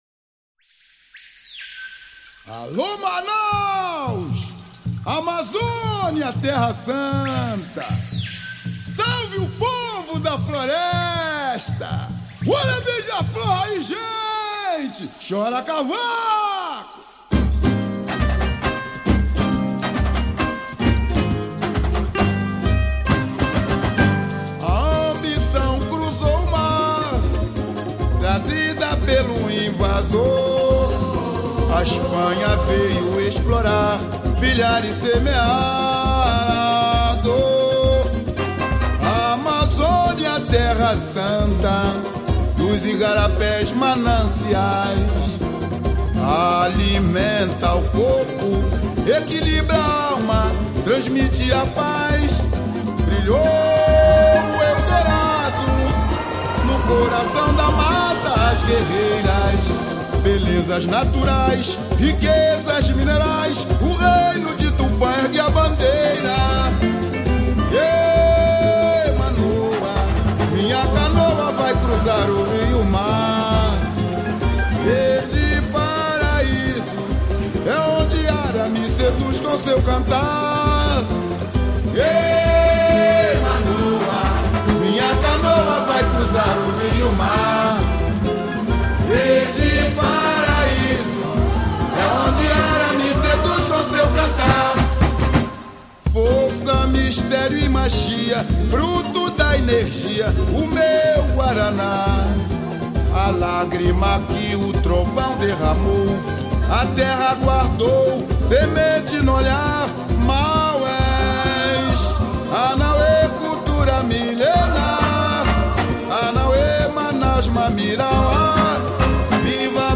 Tamborimlinie